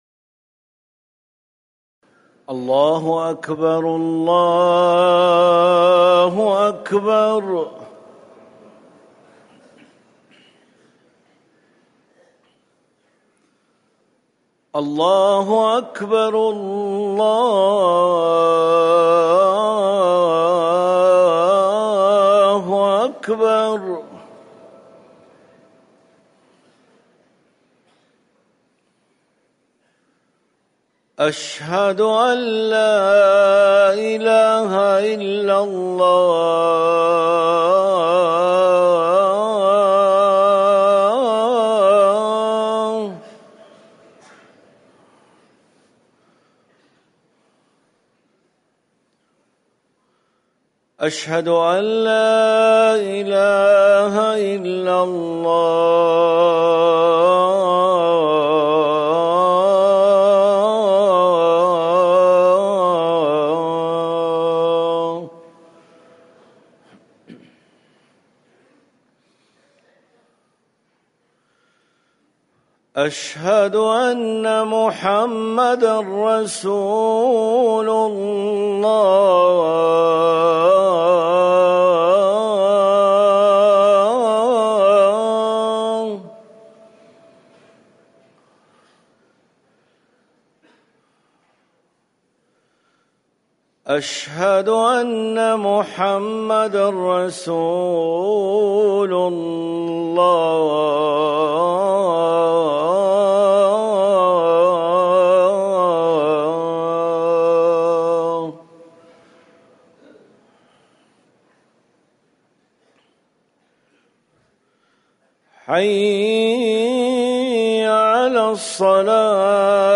أذان الفجر الأول - الموقع الرسمي لرئاسة الشؤون الدينية بالمسجد النبوي والمسجد الحرام
تاريخ النشر ١٥ صفر ١٤٤١ هـ المكان: المسجد النبوي الشيخ